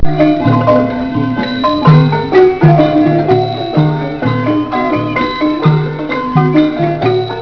Traditional Laos band with music
Parasol Blanc Hotel, Luang Prabang, Laos